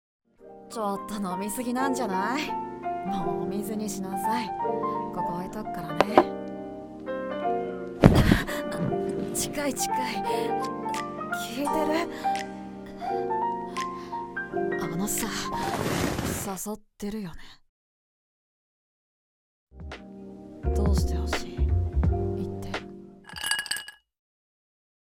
誘ってるよね 【シチュボ 1人声劇】